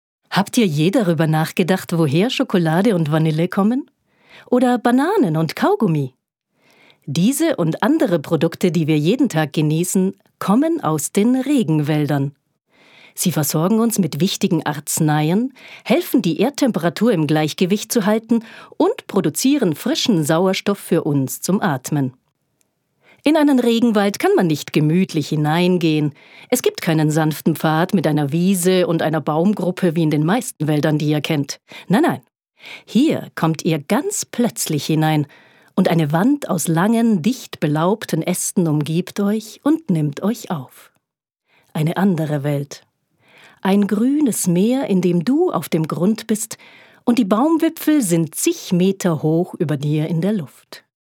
Vielseitige Schauspielerin und SĂ€ngerin mit klarer, charaktervoller, direkter Sprache - Mezzosopran.
Sprechprobe: eLearning (Muttersprache):